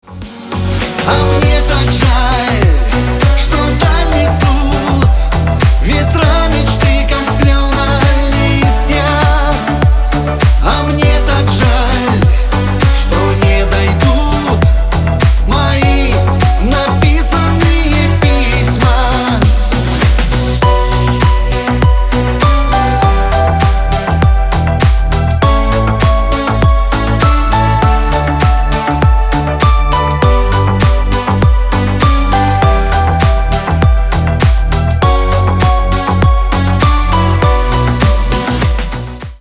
грустные , душевные
поп , русские , шансон